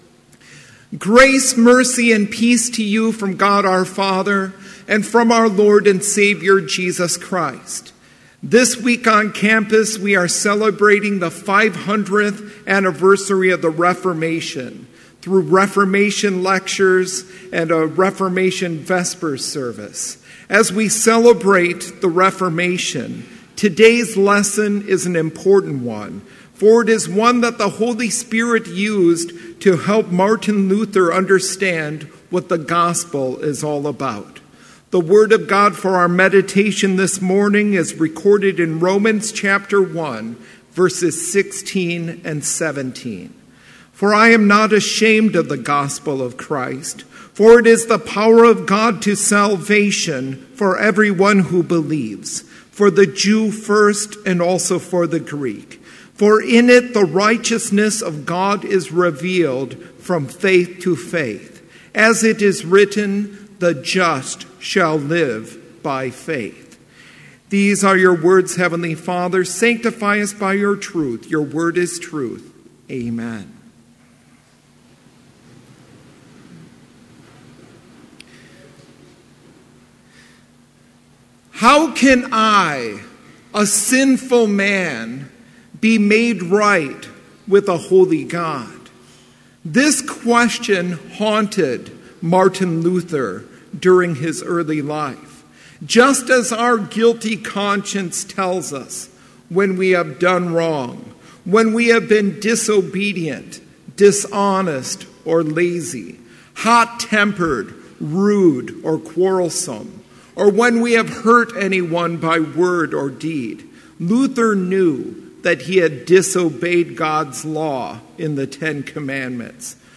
Complete Service
• Prelude
• Hymn 378, vv. 1-4, Dear Christians One and All, Rejoice!
This Chapel Service was held in Trinity Chapel at Bethany Lutheran College on Tuesday, October 24, 2017, at 10 a.m. Page and hymn numbers are from the Evangelical Lutheran Hymnary.